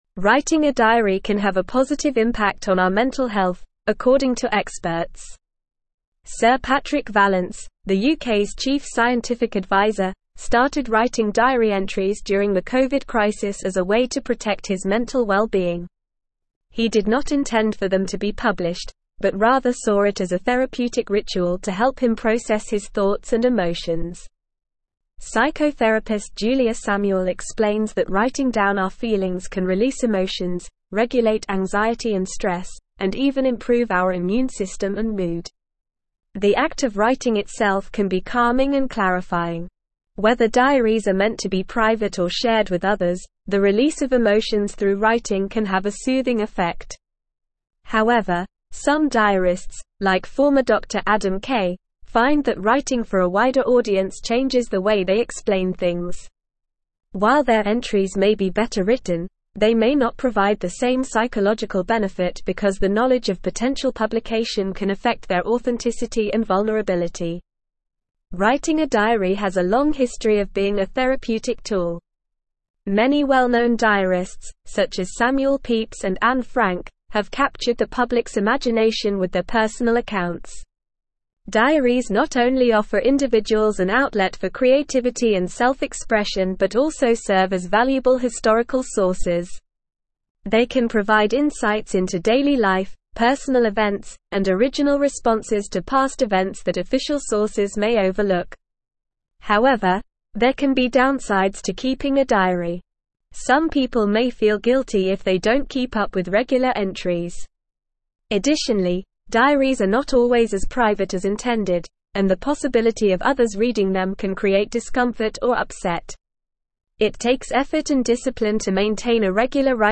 Normal
English-Newsroom-Advanced-NORMAL-Reading-The-Therapeutic-Benefits-of-Writing-a-Diary.mp3